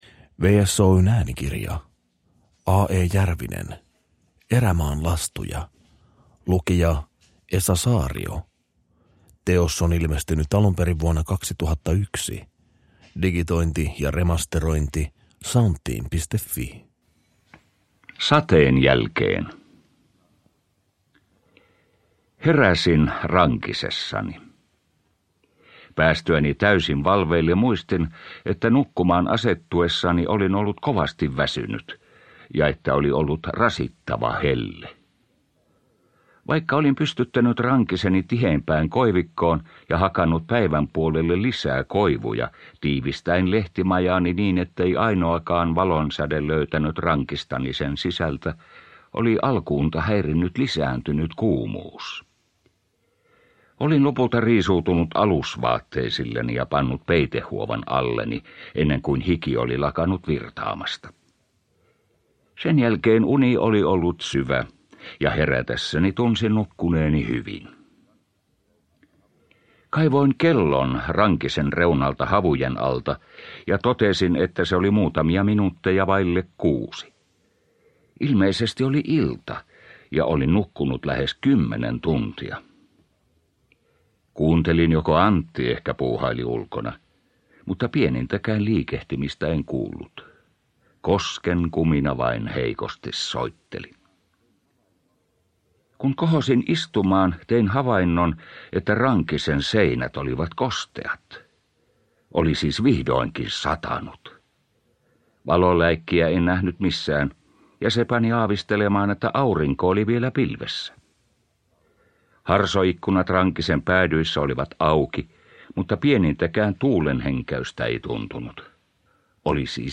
Erämaan lastuja – Ljudbok – Laddas ner